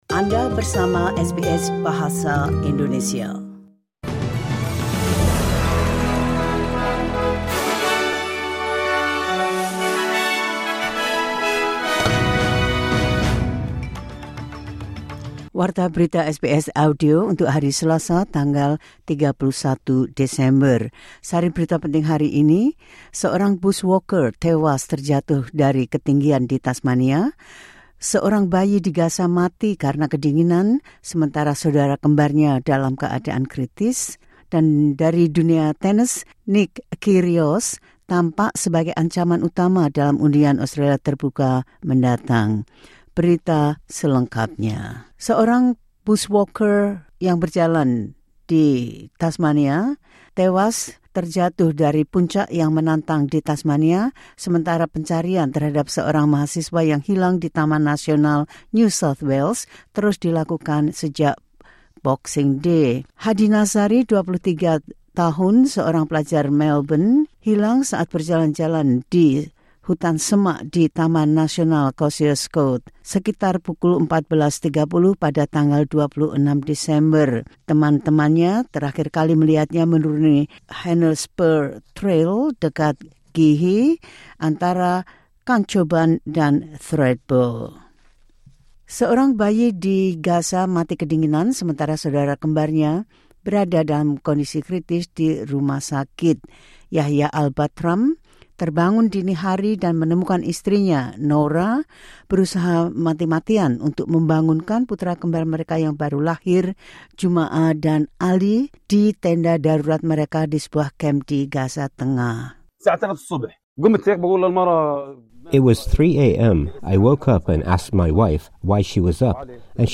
Berita terkini SBS Audio Program Bahasa Indonesia – 31 Desember 2024
The latest news SBS Audio Indonesian Program – 31 December 2024.